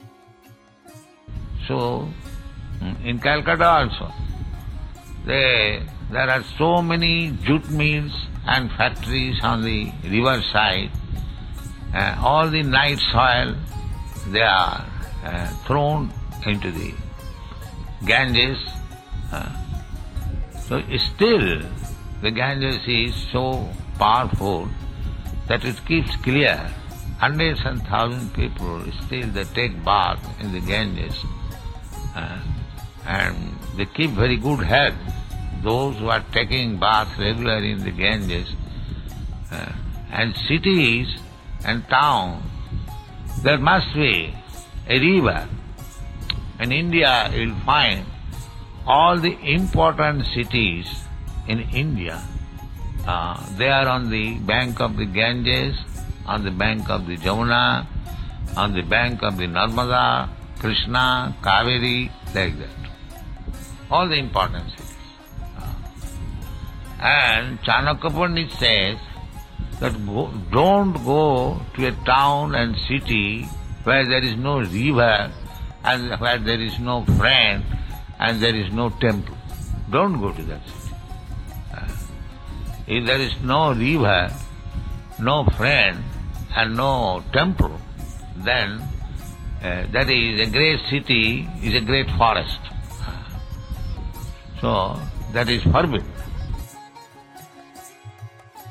(741020 - Lecture SB 01.08.40 - Mayapur)